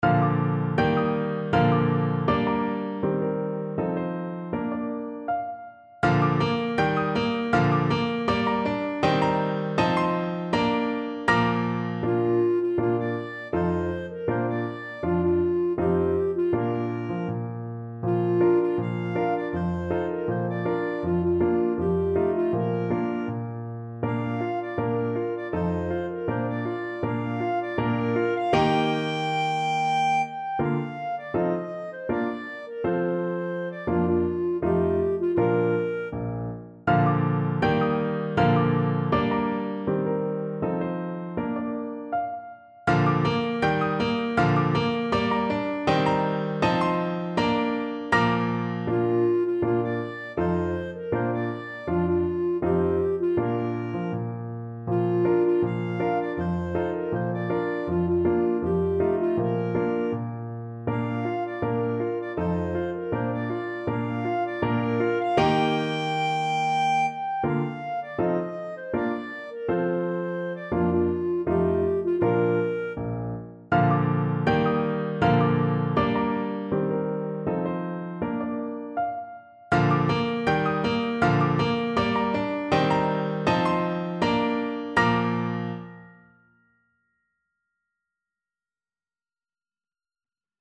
Clarinet version
2/4 (View more 2/4 Music)
Andante